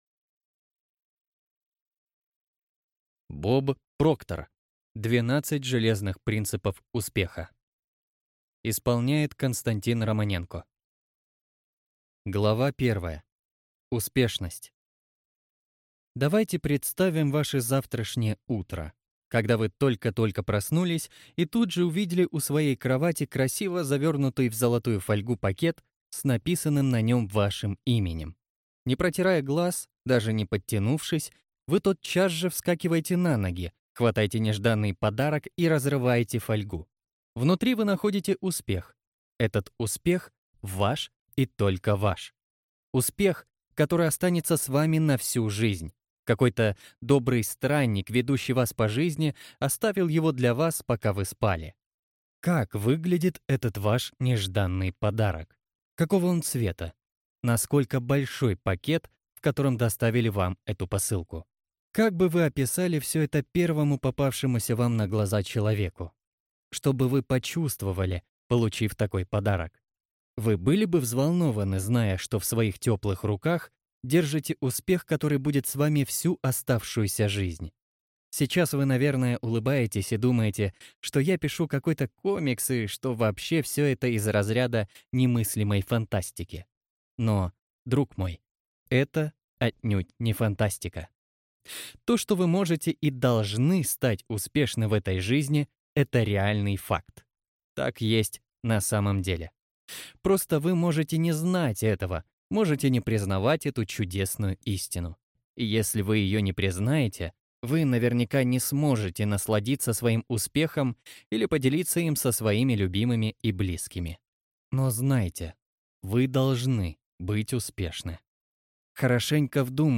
Аудиокнига 12 железных принципов успеха | Библиотека аудиокниг